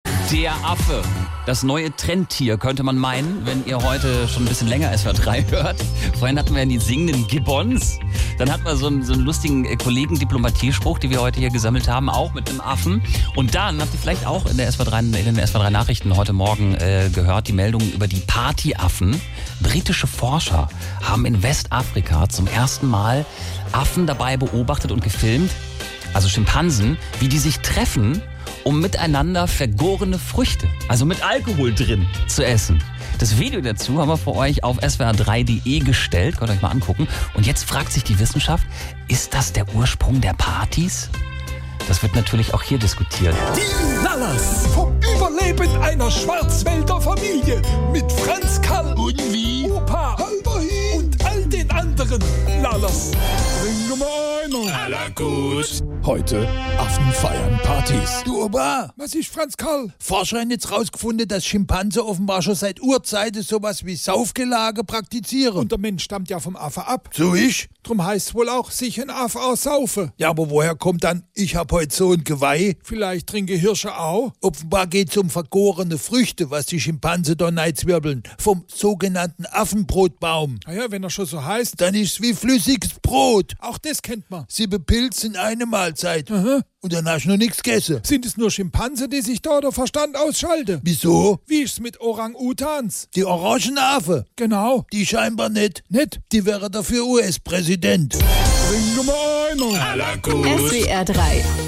SWR3 Comedy Die Lallers: Affen feiern Partys